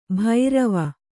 ♪ bhairava